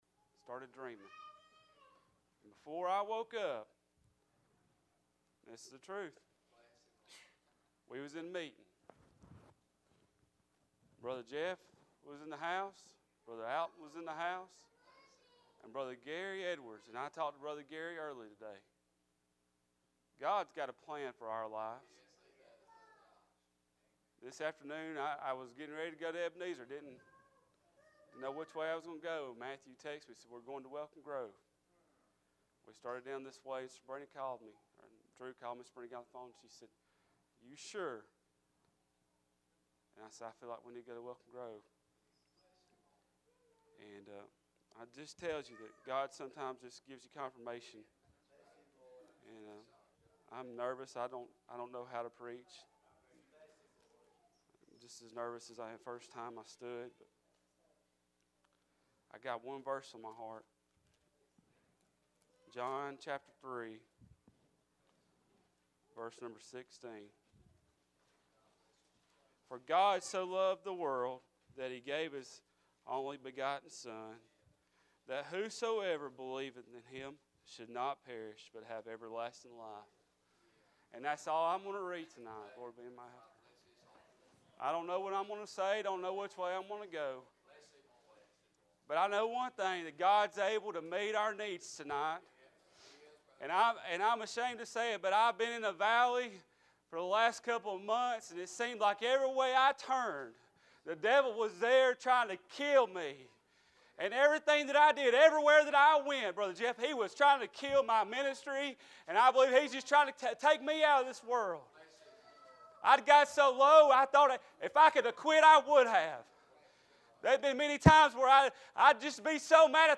God So Loved the World | Sermon
Sermon media